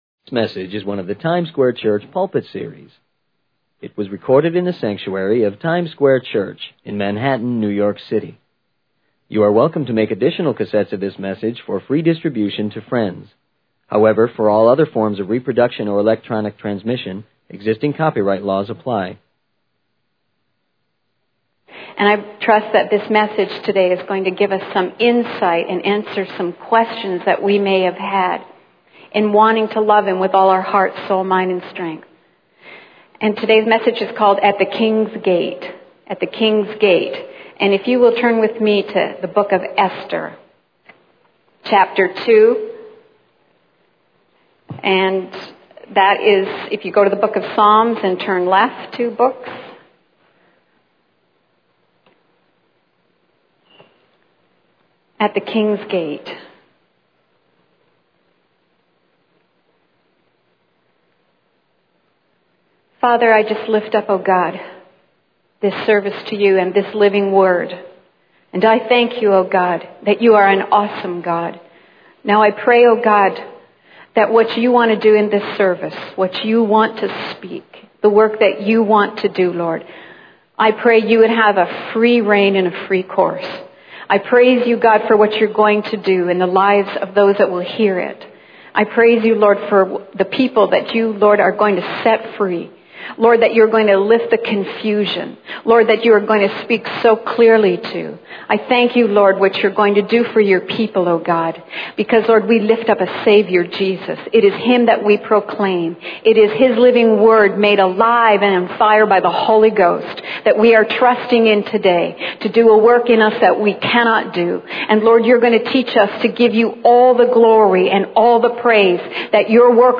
In this sermon titled 'At the King's Gate,' the speaker focuses on the story of Esther in the Bible.
It was recorded in the sanctuary of Times Square Church in Manhattan, New York City.